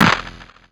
Clap (IFHY).wav